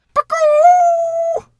Animal_Impersonations
chicken_2_bwak_09.wav